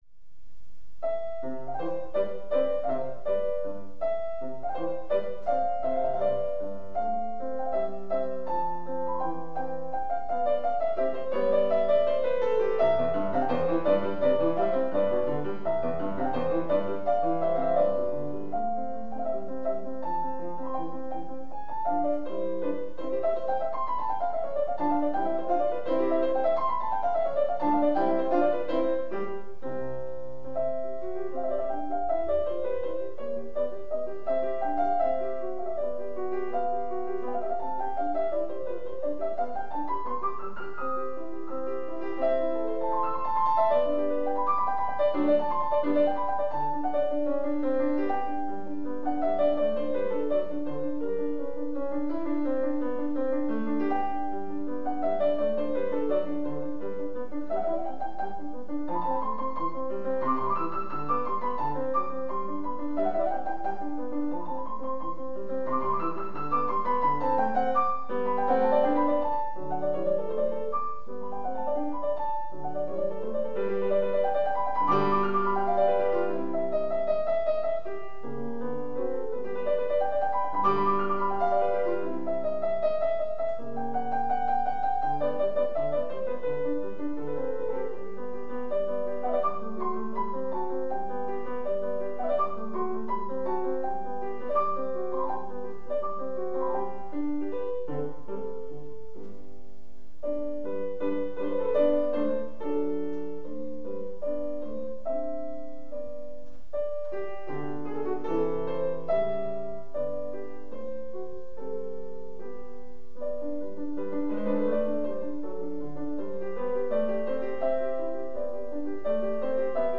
PIANIST & PAINTER
SOLO
Wolfgang Amadeus Mozart: Sonate C-dur/do majeur/ c major, KV 330